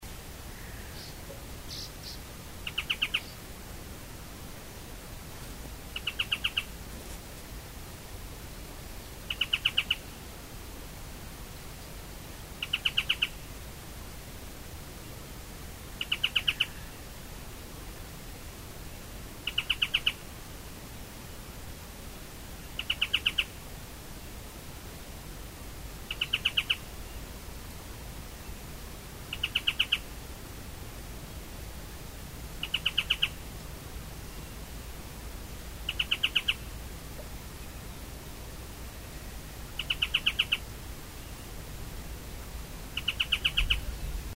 Ochre-flanked Tapaculo (Eugralla paradoxa)
Location or protected area: Valdivia
Condition: Wild
Certainty: Recorded vocal
Eugralla_paradoxa_valdivia_iphone.mp3